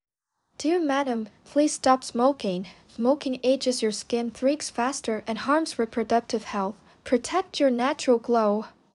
10-19 female.wav